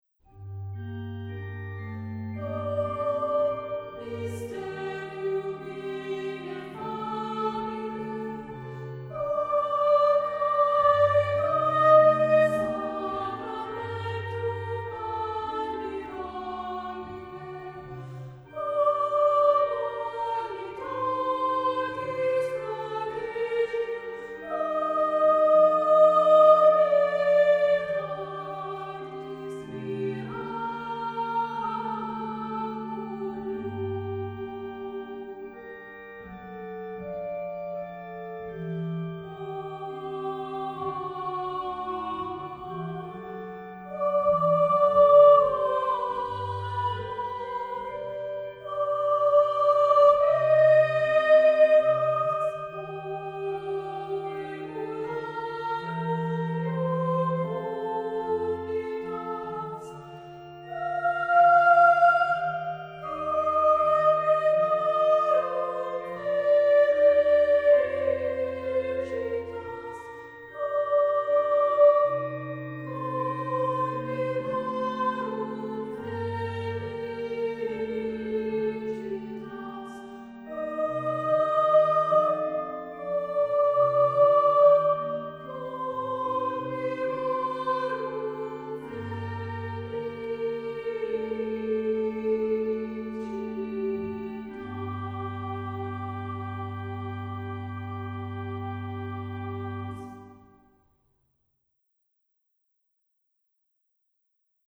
Accompaniment:      With Organ
Music Category:      Christian
two brief anthems for choir and organ